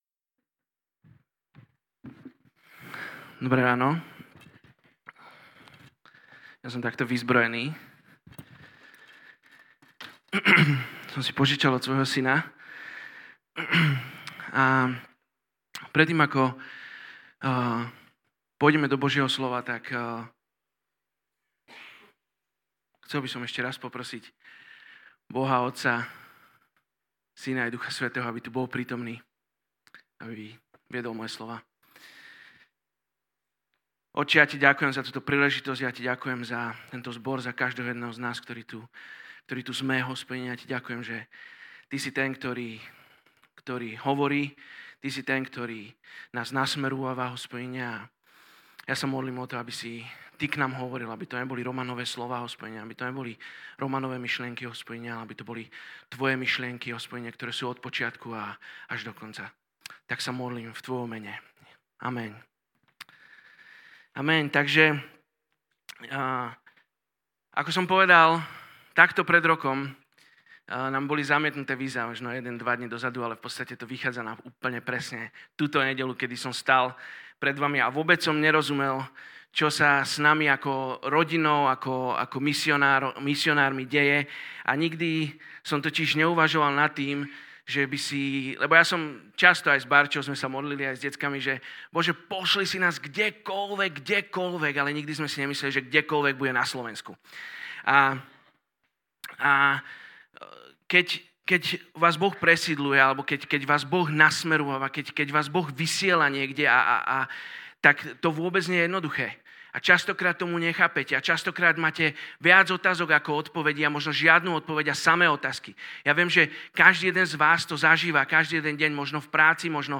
Misijná nedeľa je špeciálny formát bohoslužieb, na ktorom sa prioritne zameriavame na misiu s víziou formovania misijného povedomia v každom z nás. Akým spôsobom prvá cirkev vysielala misionárov? V biblickej knihe Skutkov nachádzame niekoľko prípadov, kedy cirkev vysiela ľudí na misiu.